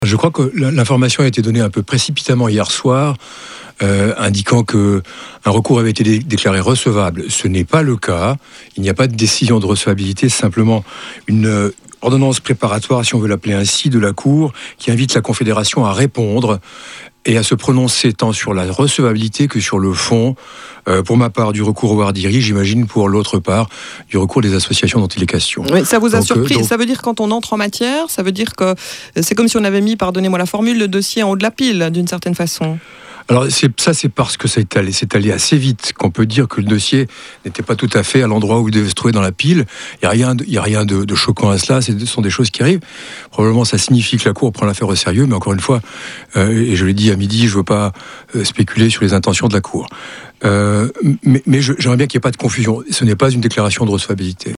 avocat d’une des parties recourantes